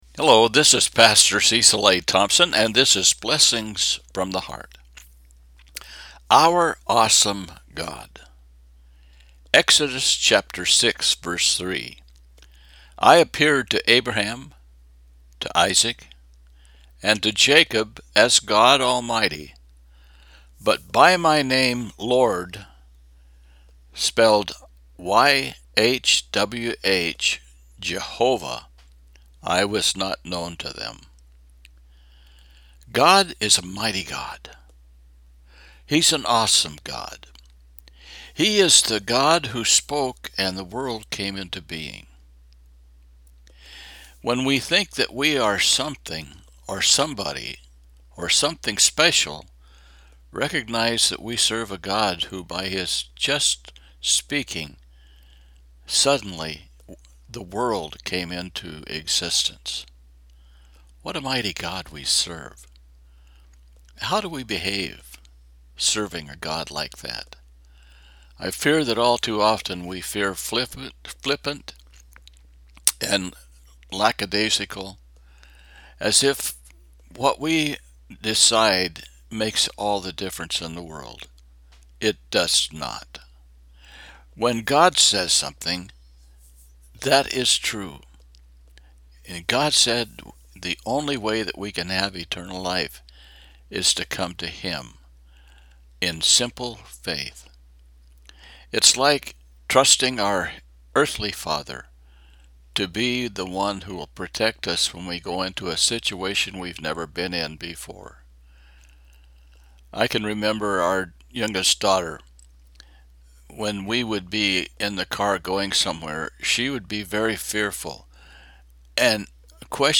Exodus 8:23 – Devotional